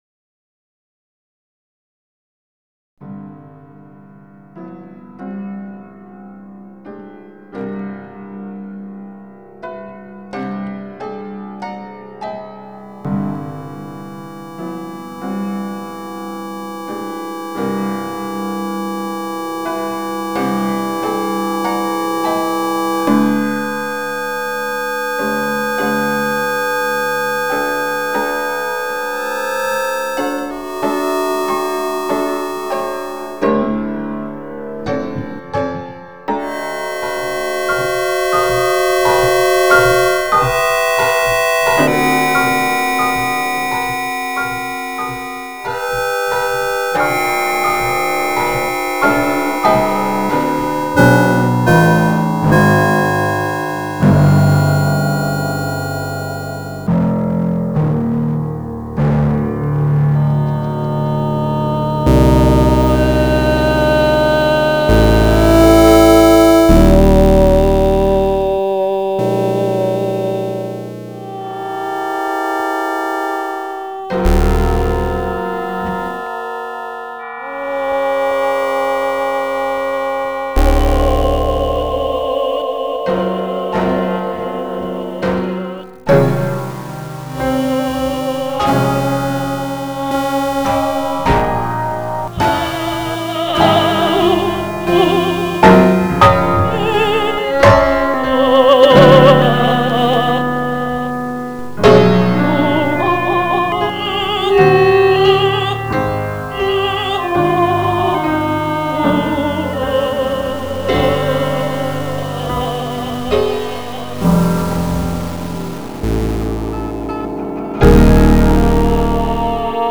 per mezzosoprano, pianoforte e computer